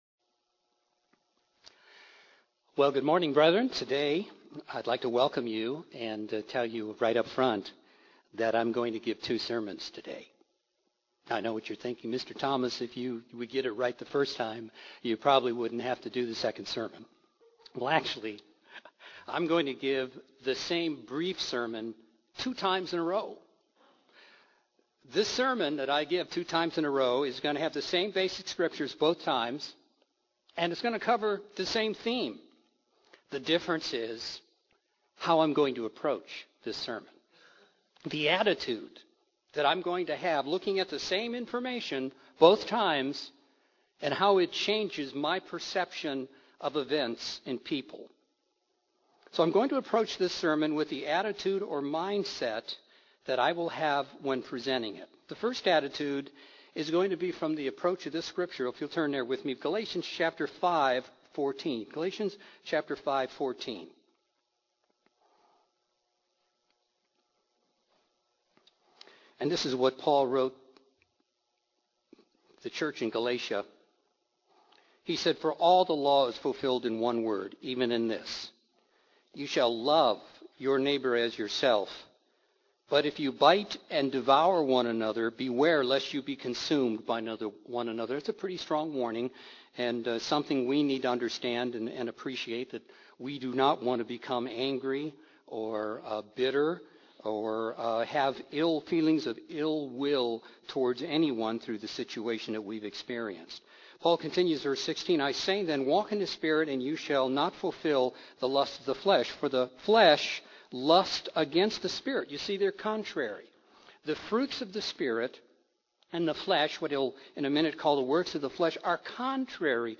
This sermon demonstrates how you can view two exact events or situations and come to very different conclusions. Two mini-sermons are given on the same topic and using the same key scriptures.